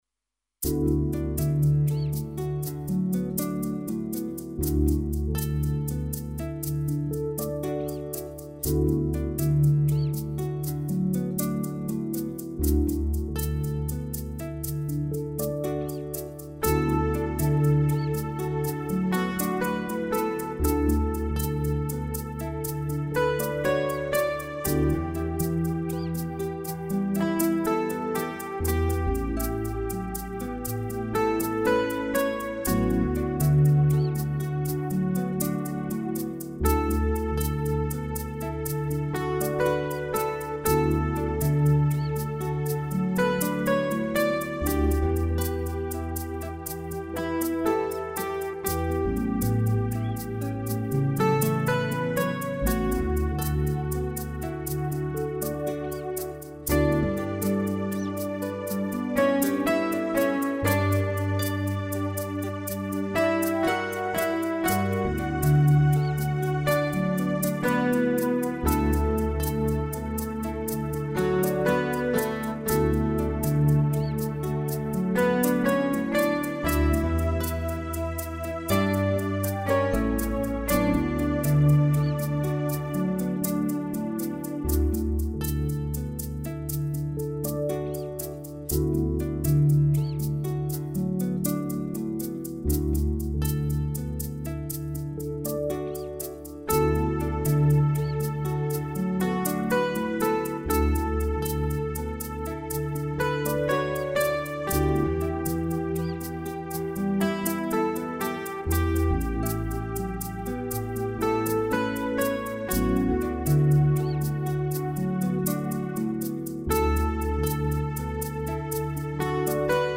MUSICA PER LA GRAVIDANZA E IL NEONATO